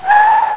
added skid sound for cars
skid.ogg